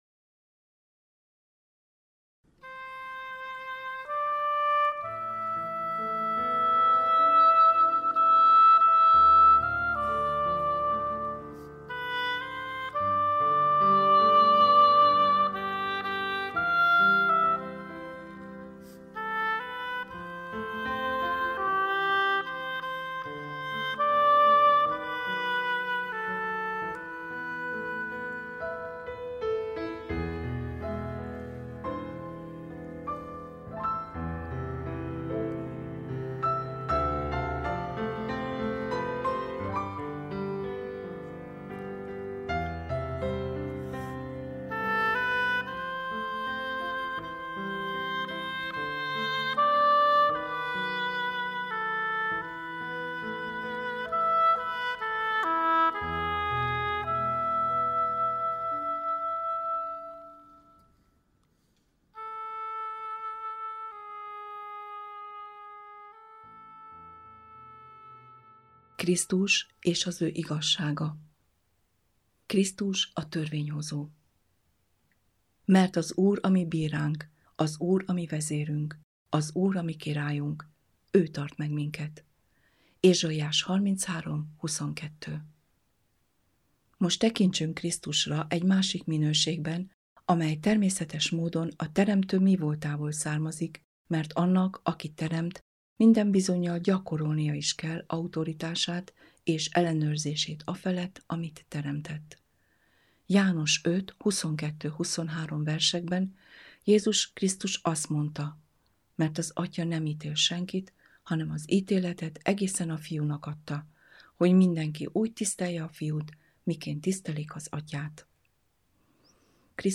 Krisztus-a-torvenyhozo-zenevel.mp3